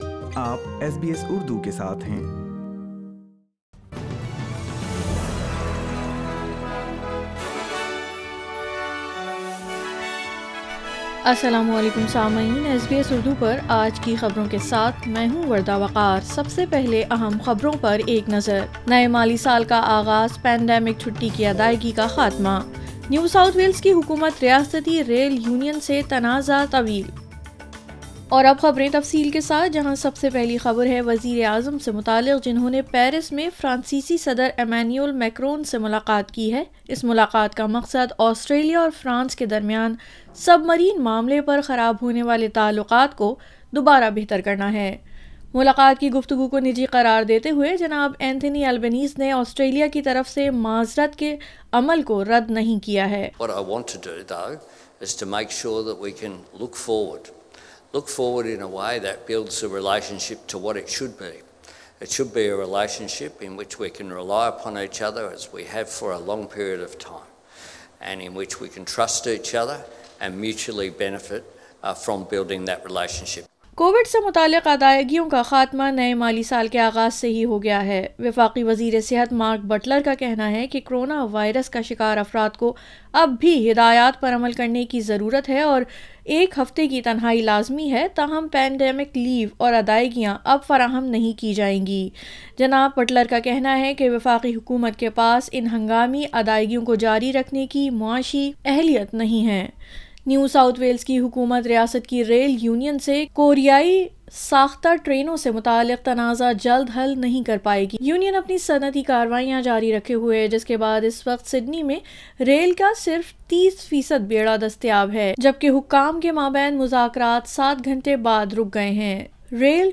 SBS Urdu News 01 July 2022